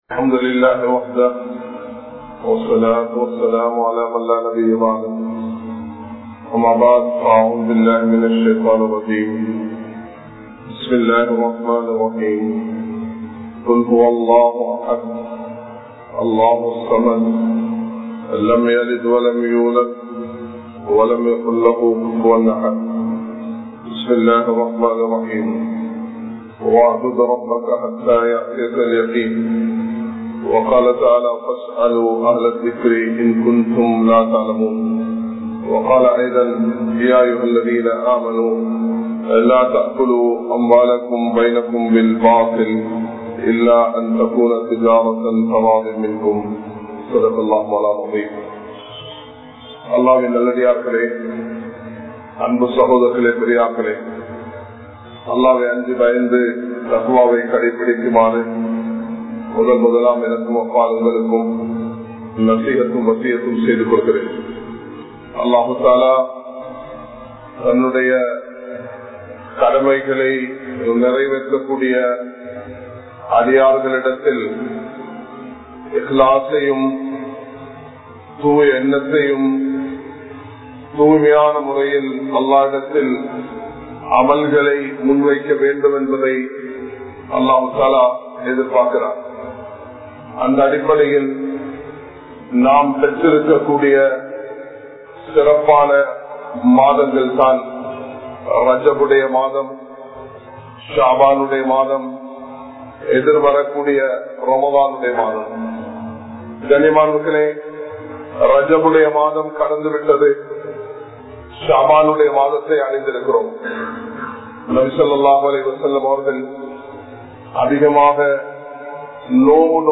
Ramalaanukku Neengal Thayaara? (ரமழானுக்கு நீங்கள் தயாரா?) | Audio Bayans | All Ceylon Muslim Youth Community | Addalaichenai
Kollupitty Jumua Masjith